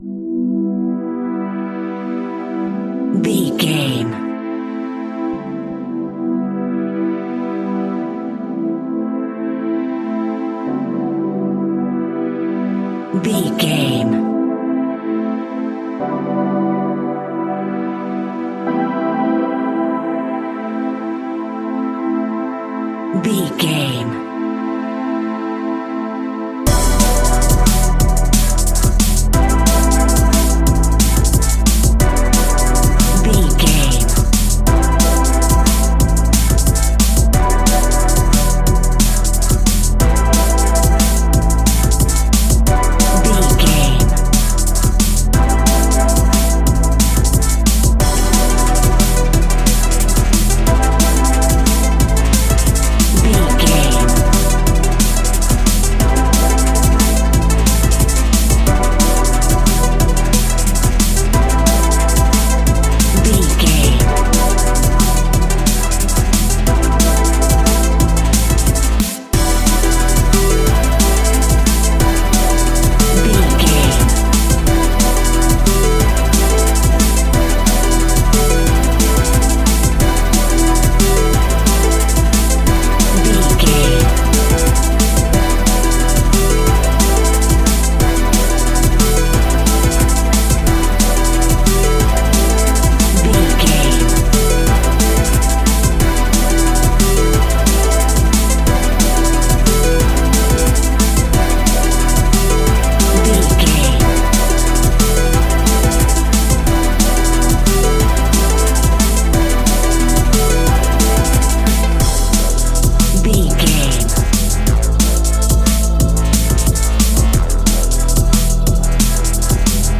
Epic / Action
Fast paced
Aeolian/Minor
aggressive
dark
driving
energetic
intense
futuristic
synthesiser
drum machine
electronic
sub bass
synth leads
synth bass